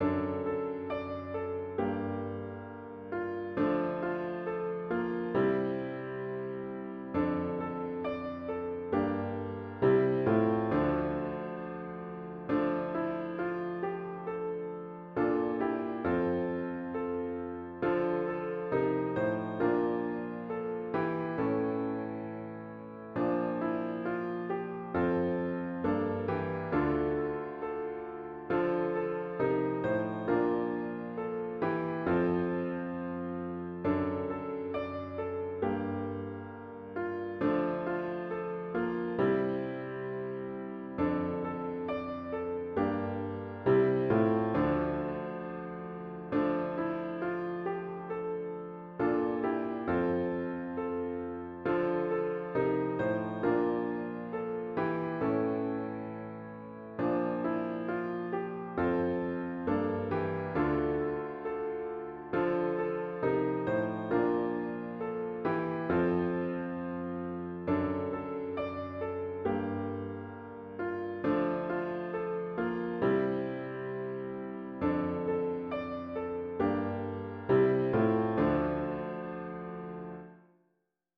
HYMN “Let All Mortal Flesh Keep Silence” GtG 347 (Verses 1-2)